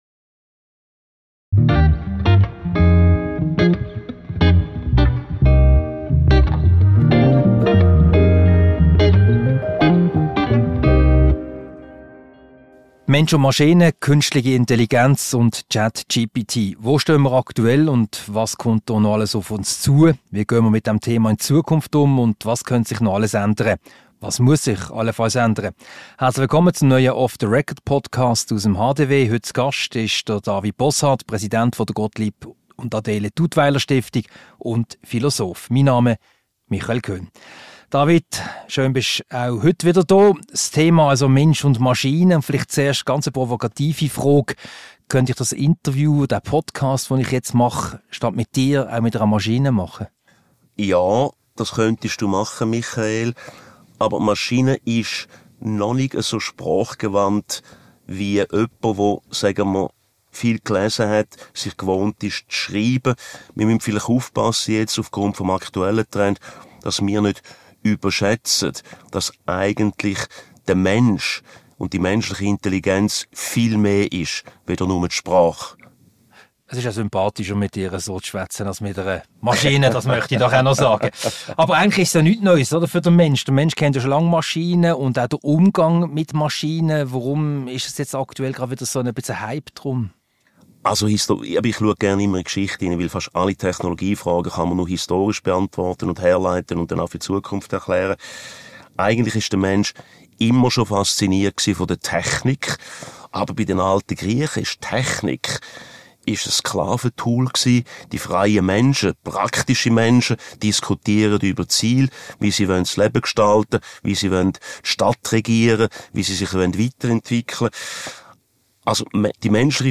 Ein Gespräch über künstliche Intelligenz: Der Mensch und die Maschine. Wo stehen wir heute, was kommt noch alles auf uns zu, was könnte sich noch alles ändern – was muss sich ändern.